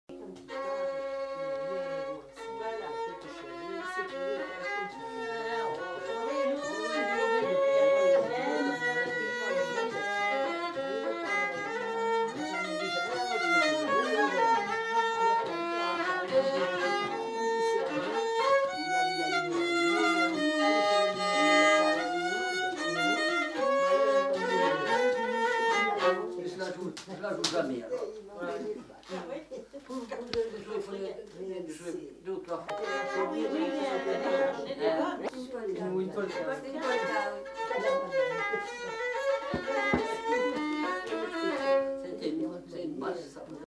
Aire culturelle : Limousin
Lieu : Lacombe (lieu-dit)
Genre : morceau instrumental
Instrument de musique : violon
Danse : valse
Notes consultables : Le second violon est joué par un des enquêteurs.